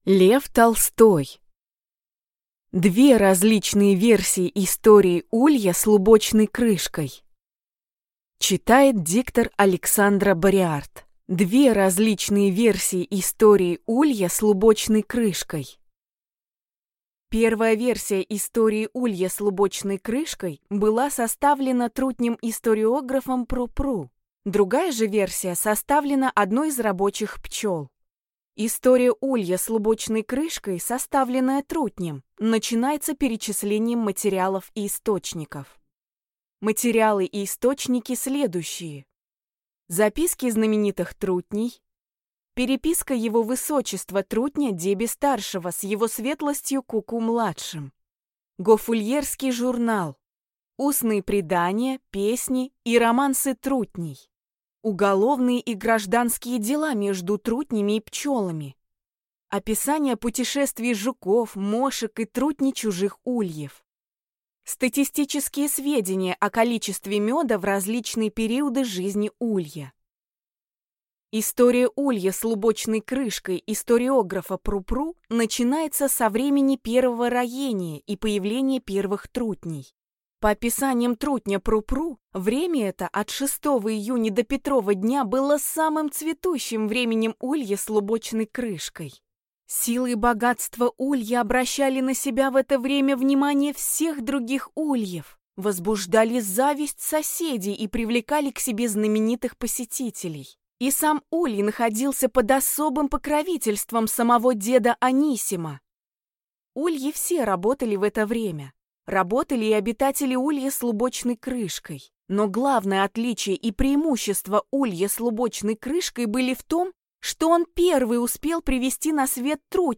Аудиокнига Две различные версии истории улья с лубочной крышкой | Библиотека аудиокниг